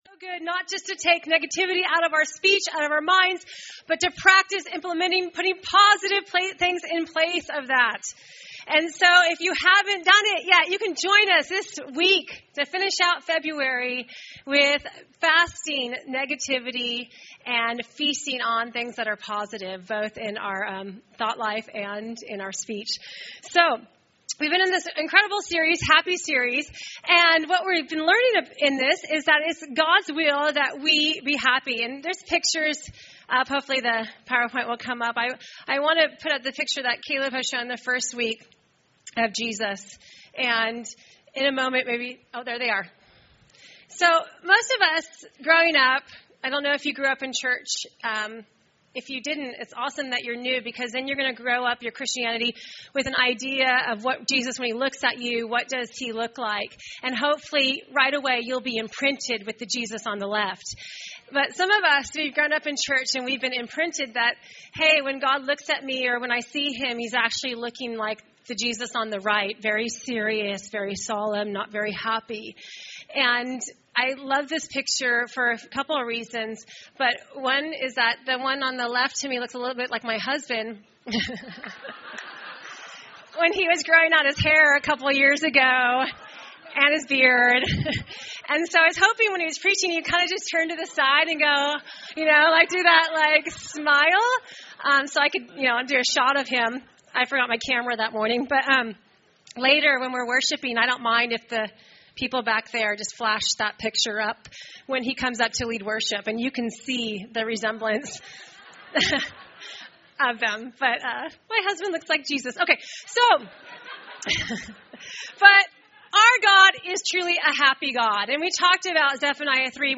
Recorded at New Life Christian Center, Sunday, February 22, 2015 at 11AM.